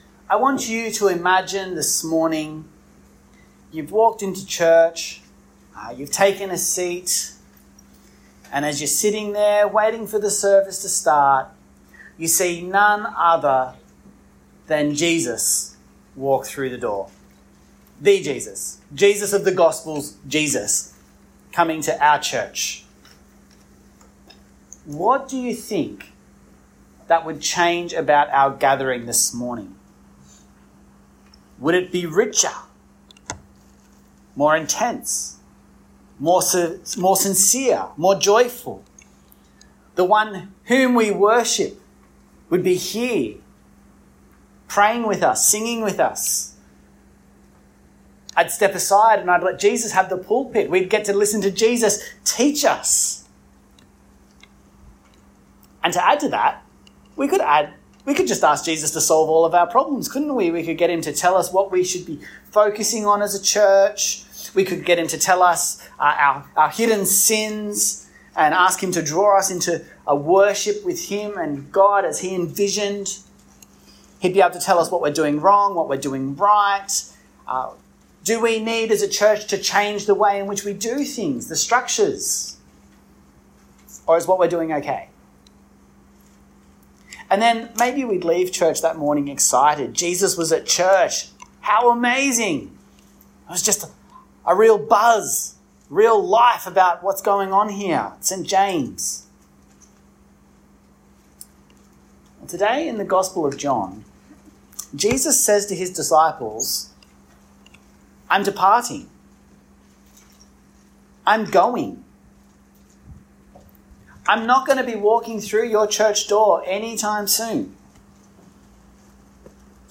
John 16 4b - 18 Sermon.mp3